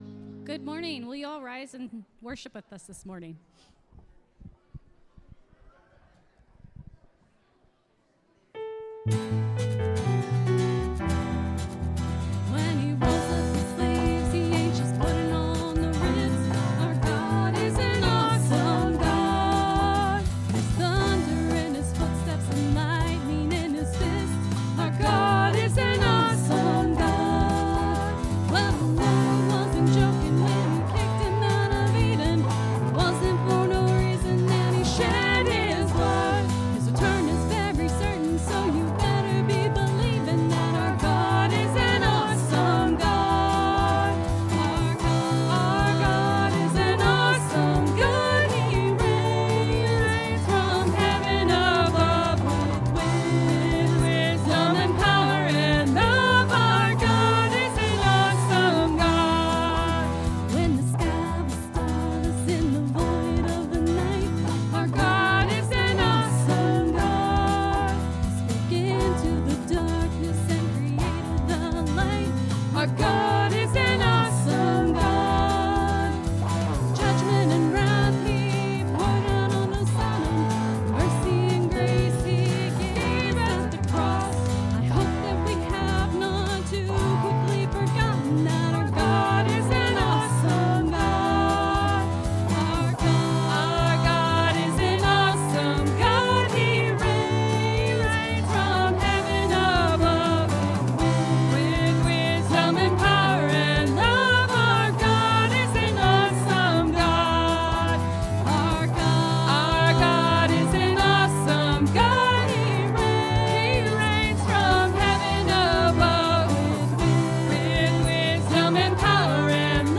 (Sermon starts at 19:20 in the recording).